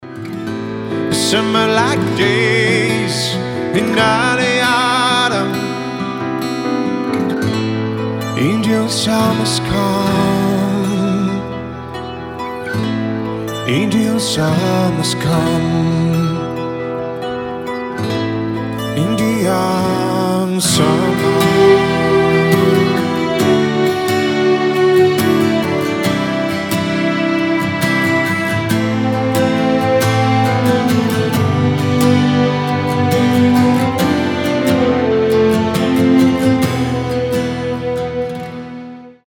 • Качество: 320, Stereo
поп
гитара
мужской вокал
мелодичные
спокойные
скрипка
теплые